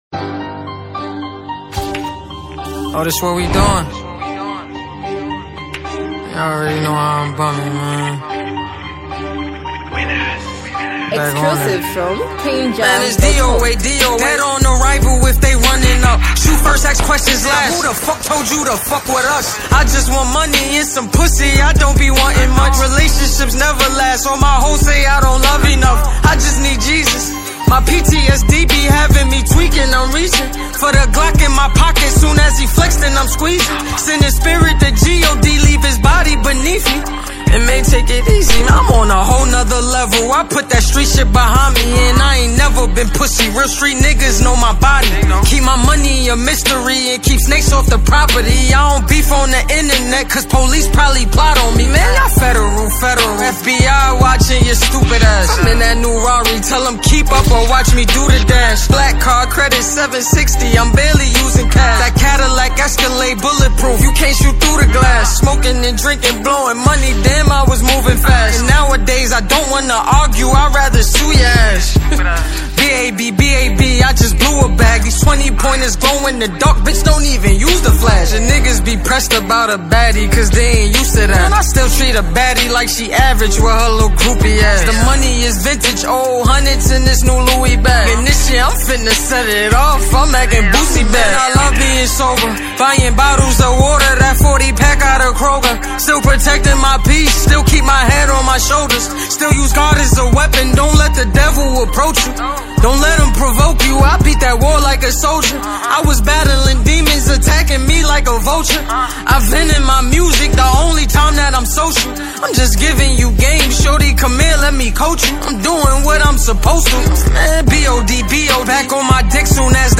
just bars over a hard beat